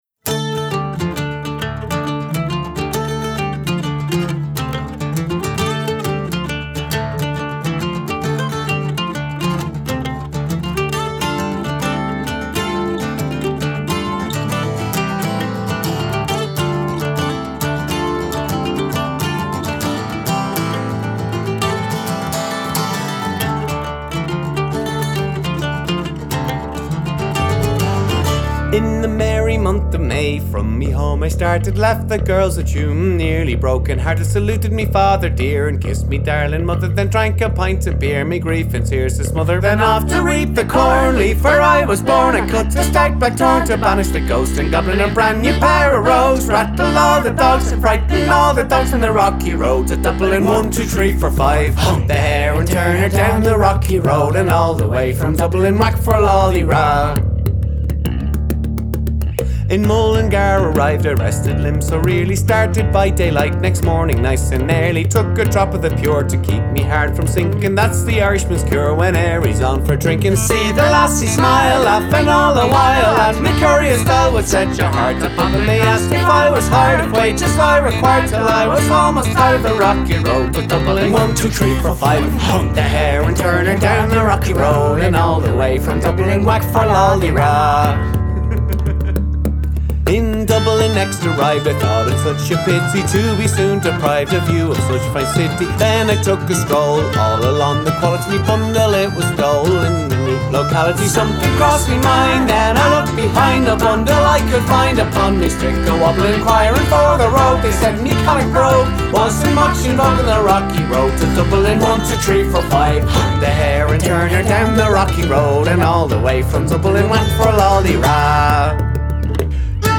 muzyki celtyckiej
flety irlandzkie, mandola, mandolina, gitara, śpiew
melodeon, kości, śpiew
skrzypce
bodhran
gitara basowa